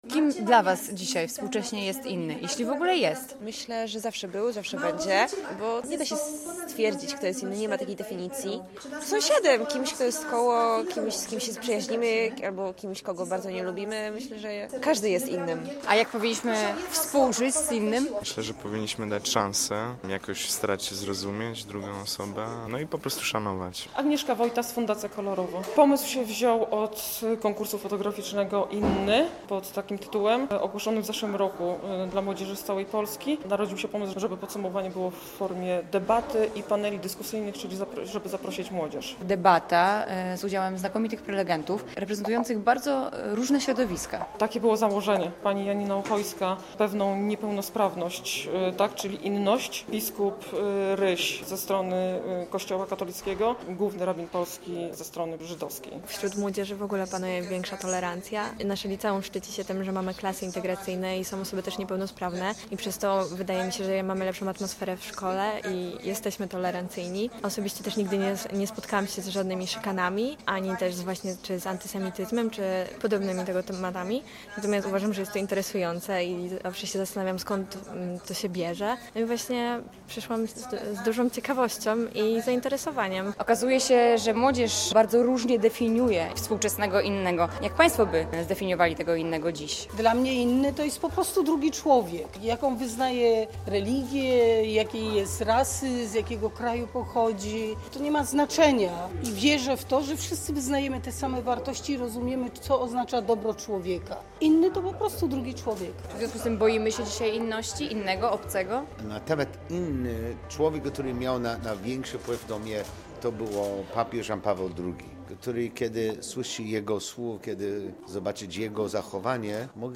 Debata w Centrum Promocji Mody Akademii Sztuk Pięknych zgromadziła ponad 160 uczniów łódzkich szkół.